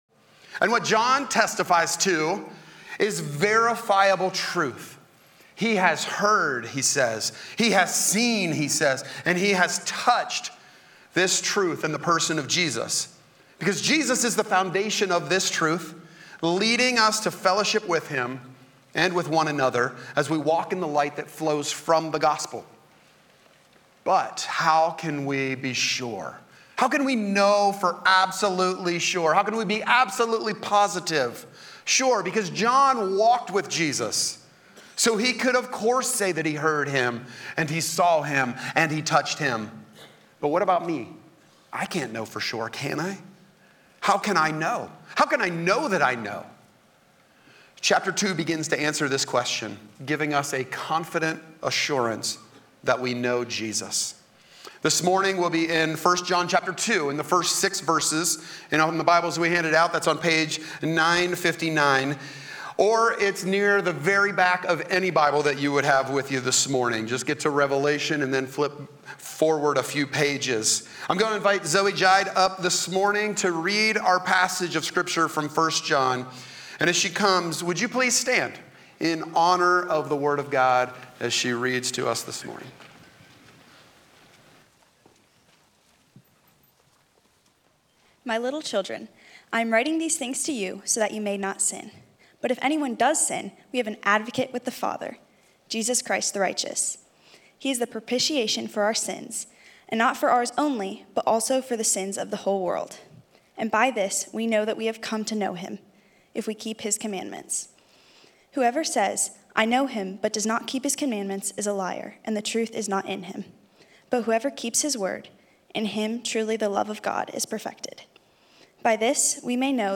A sermon from the series "By This We Know."